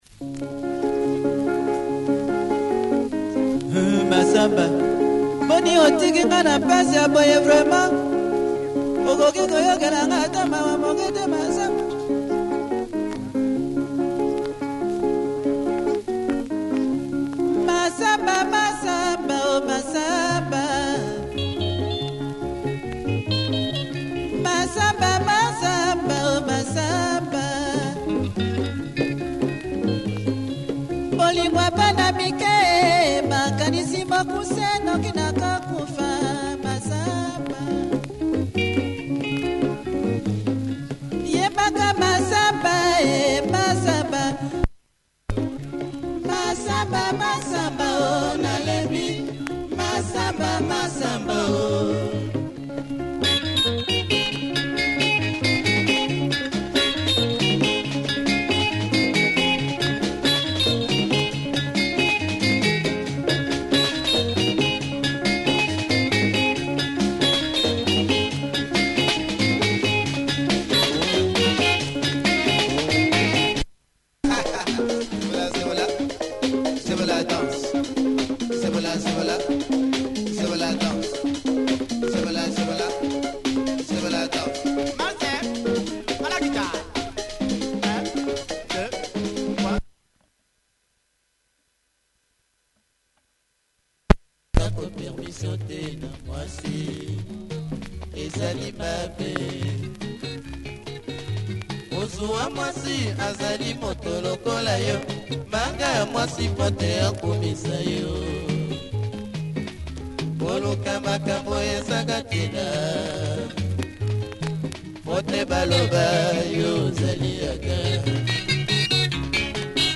Nice lingala